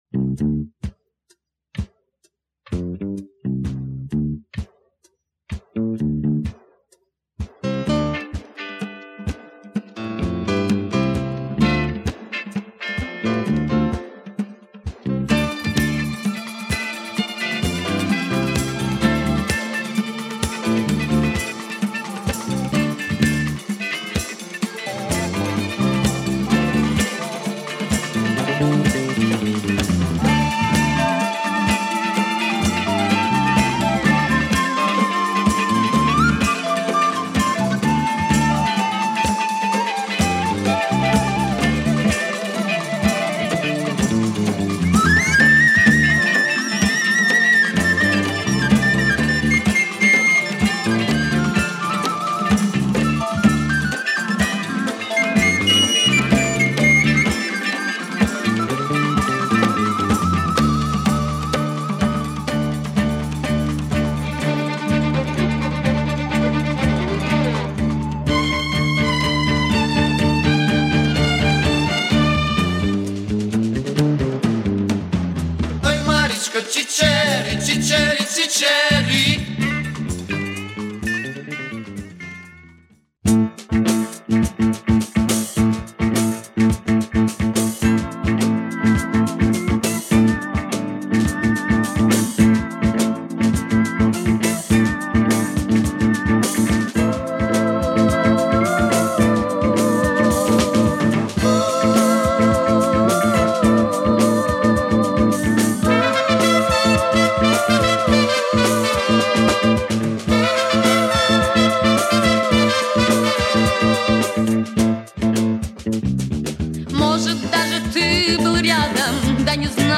Ukrainian soulful female singer